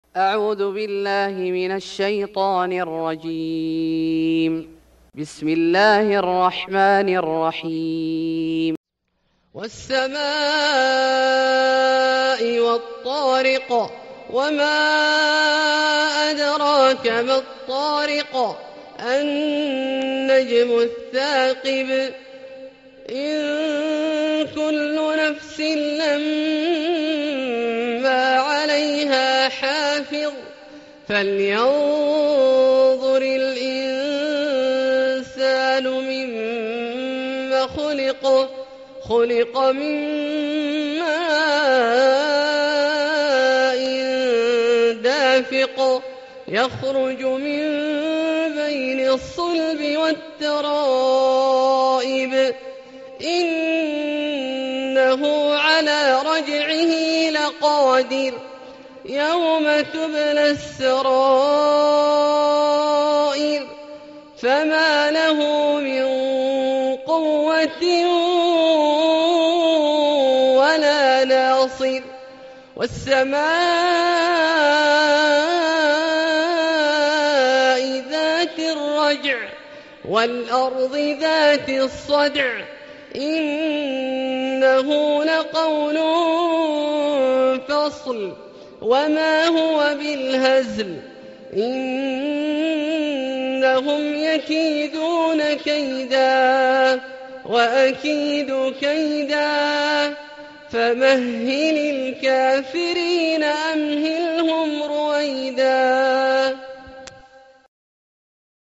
سورة الطارق Surat At-Tariq > مصحف الشيخ عبدالله الجهني من الحرم المكي > المصحف - تلاوات الحرمين